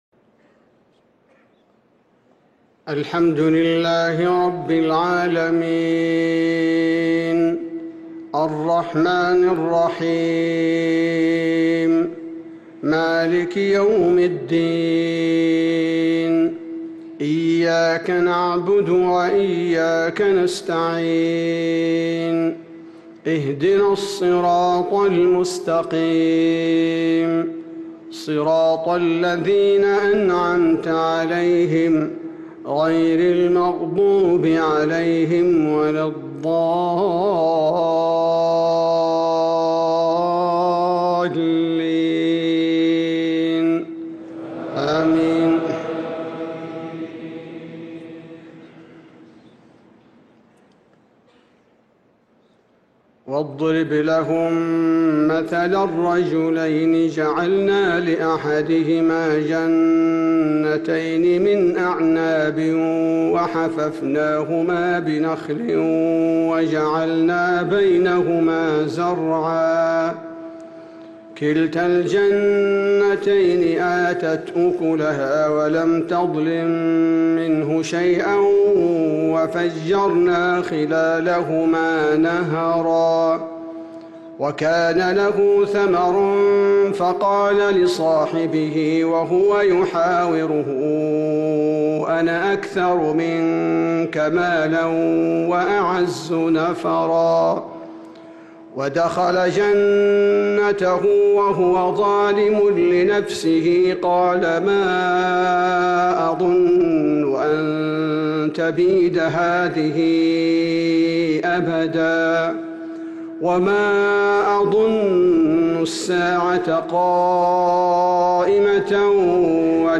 صلاة الفجر للقارئ عبدالباري الثبيتي 14 ذو القعدة 1445 هـ
تِلَاوَات الْحَرَمَيْن .